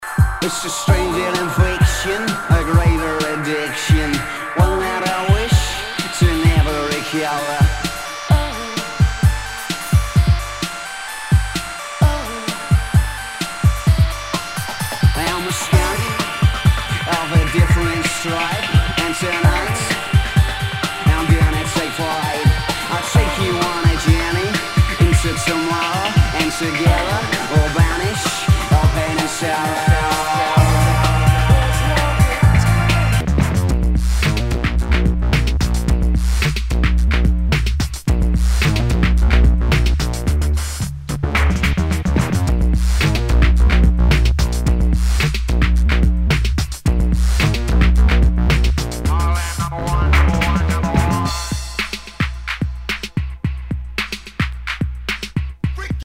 Nu- Jazz/BREAK BEATS
ナイス！ブレイクビーツ / ダウンテンポ！